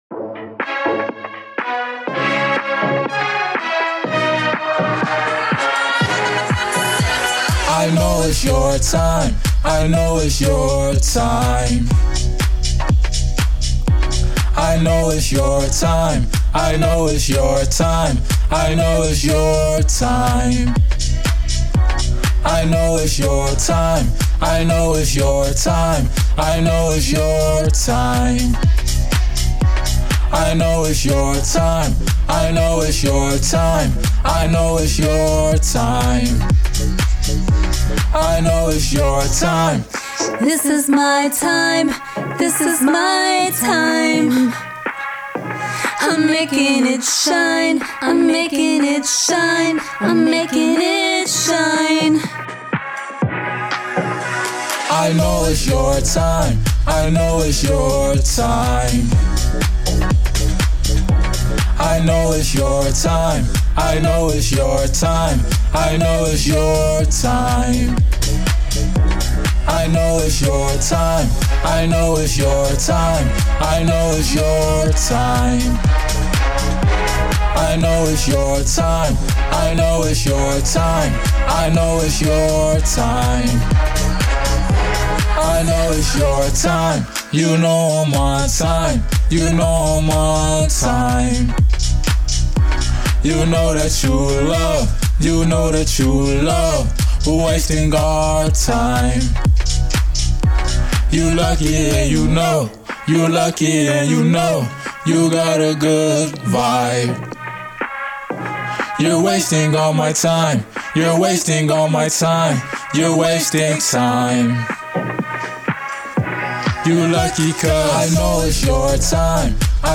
A Alternative Album Mixed With Pop And Rock As Well Enjoy!